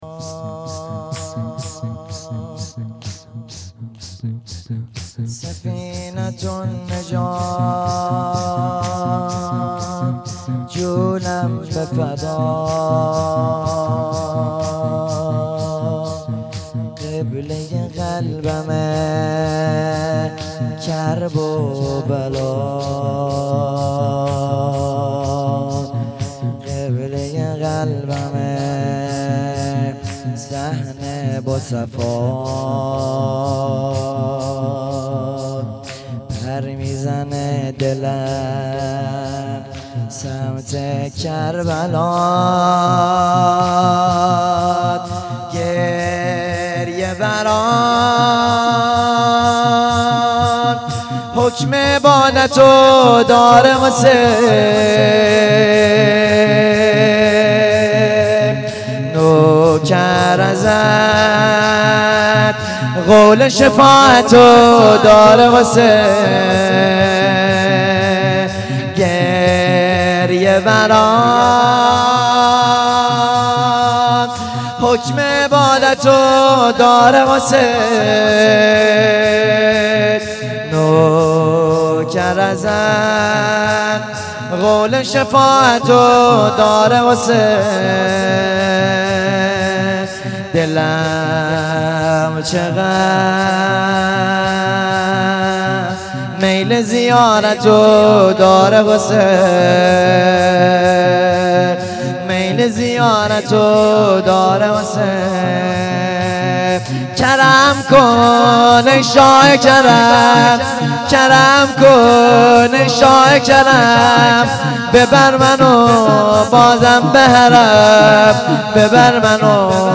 شور پایانی
مراسم هفتگی